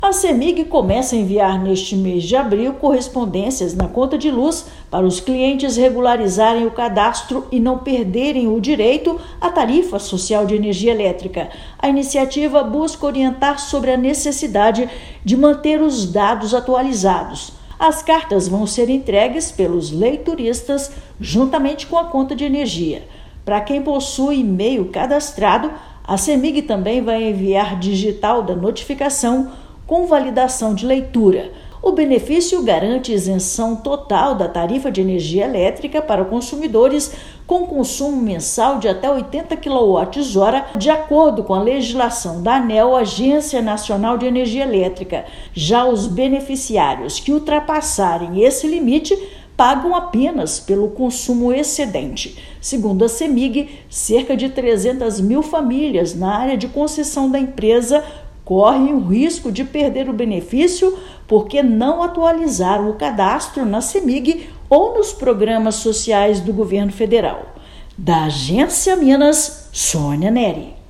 [RÁDIO] Cemig inicia ação para evitar que famílias de baixa renda percam desconto na conta de luz
Iniciativa reforça orientação para manutenção do cadastro social e continuidade do acesso ao desconto. Ouça matéria de rádio.